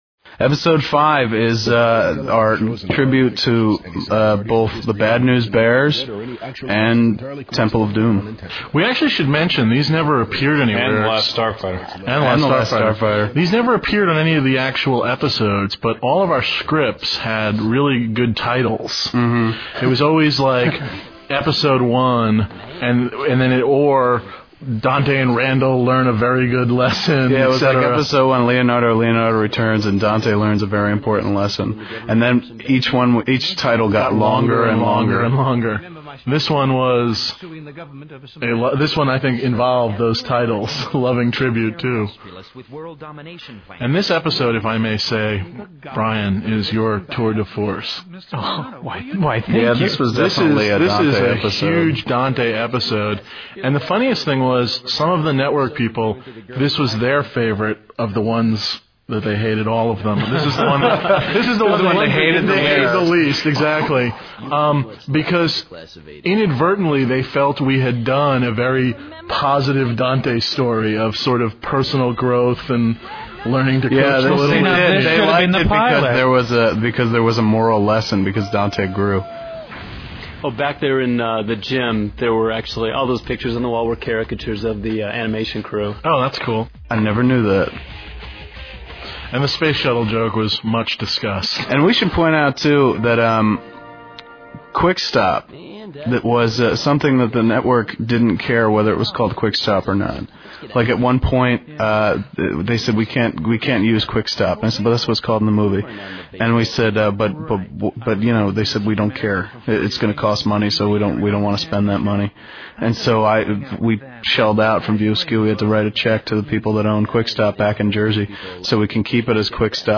Clerks S1E05 - Commentary